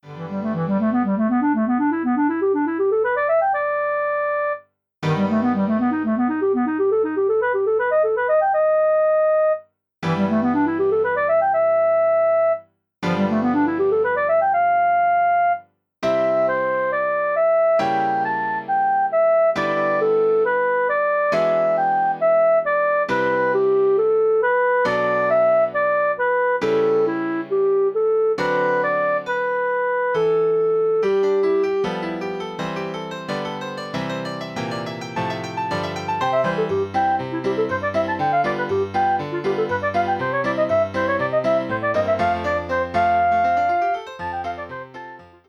All works are written for clarinet and piano.